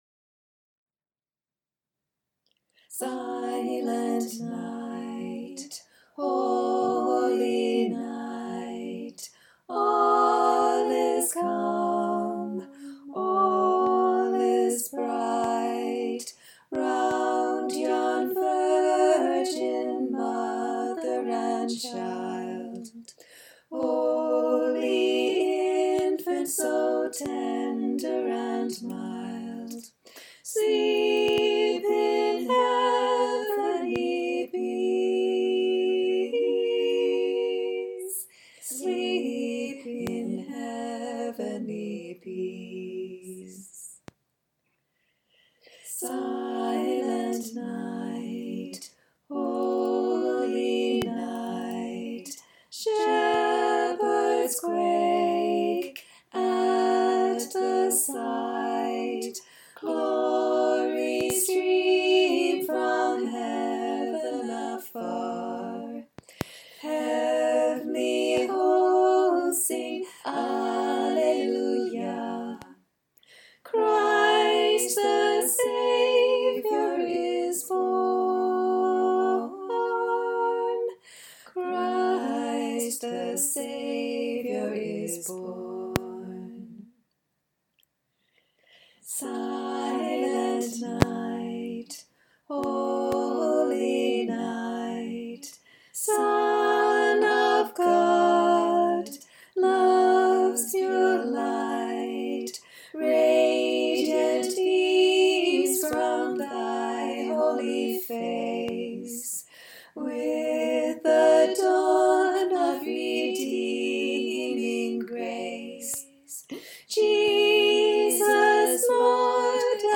Back in 2018 I had a lovely time recording 3 parts for the Librivox community choir. Here they are, combined in sincere, clunky, mono a capella.